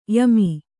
♪ yami